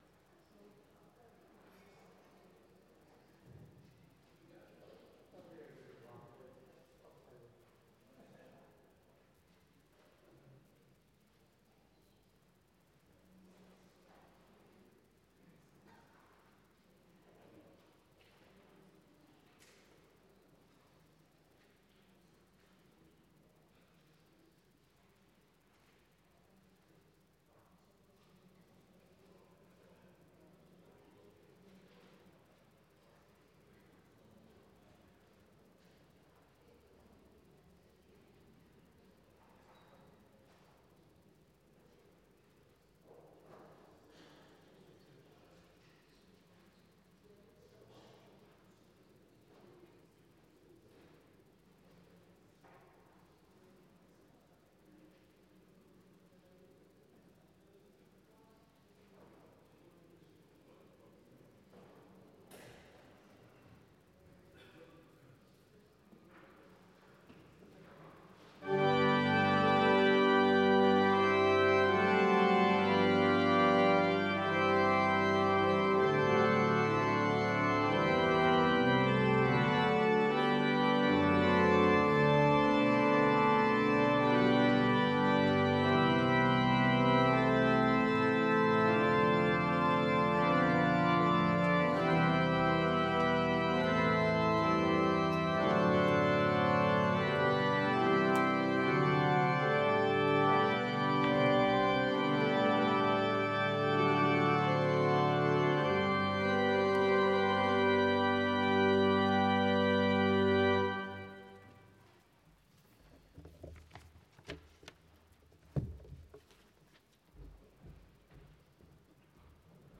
Sermon Only Audio